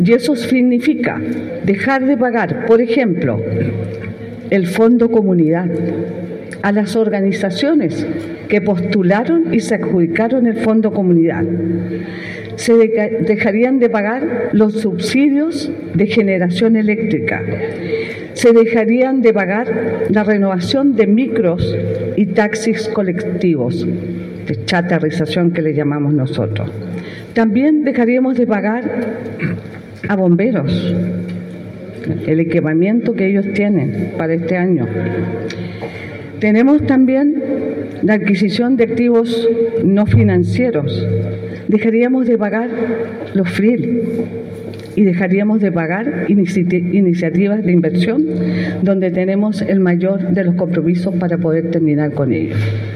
La Consejera Barraza detalló que los fondos son fundamentales para la ejecución de proyectos en sectores críticos, como el Fondo Comunidad, subsidios para la generación de energía en zonas aisladas, la renovación de la flota de transporte público, apoyos a los cuerpos de bomberos, y varios otros rubros necesarios para mejorar la calidad de vida de los habitantes.